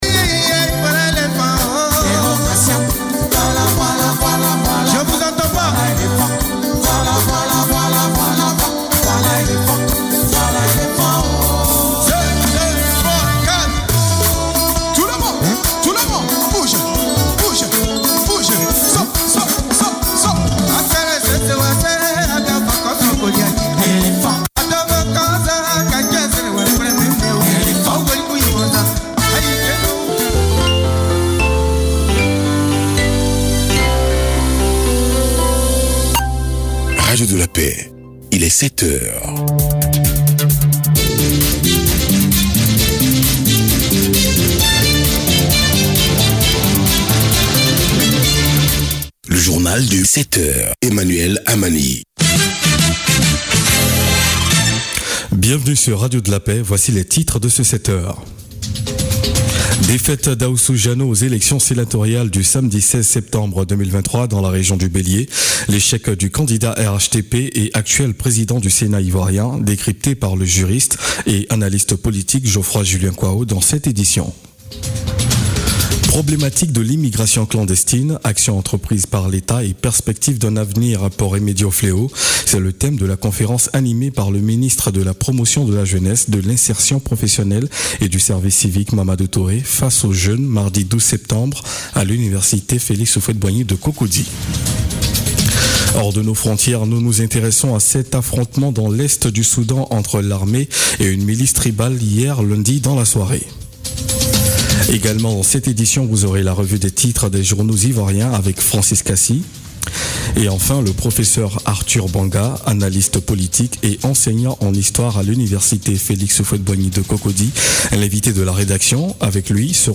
Journal de 18H du 8 Avril 2019